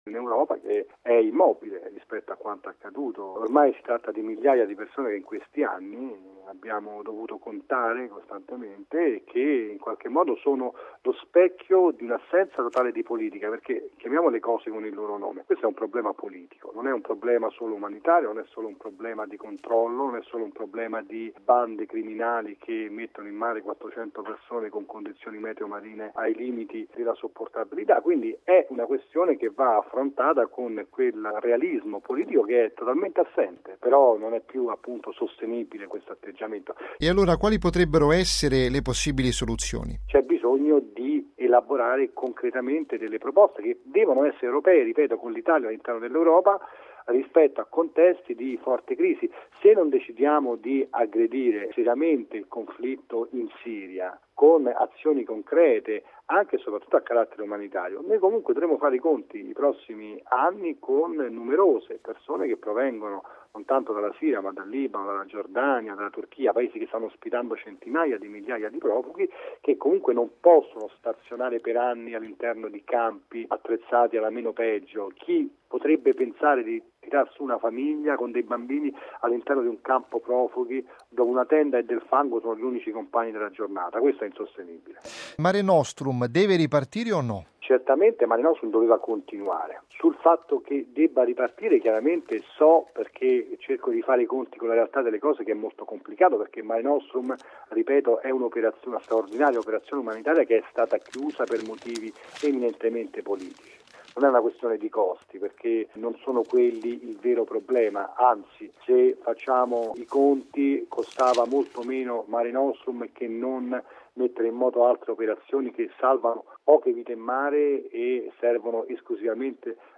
lo ha intervistato: